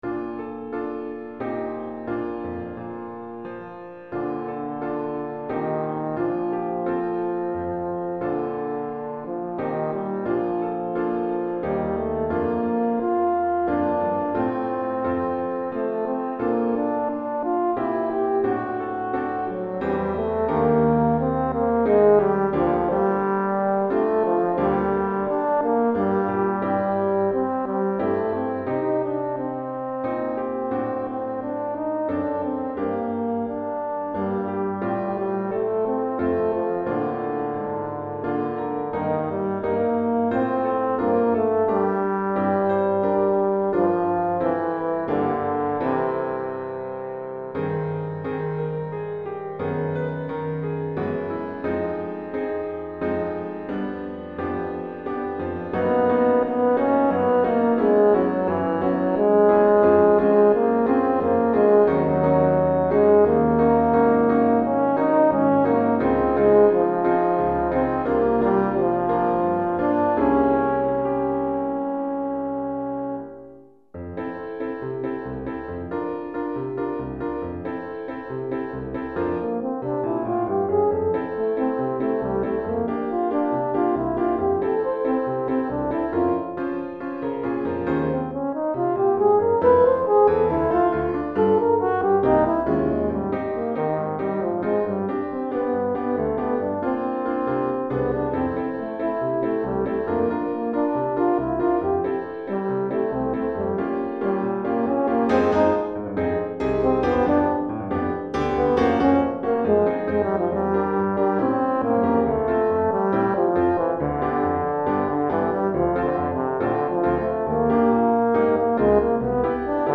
Cor en Fa et Piano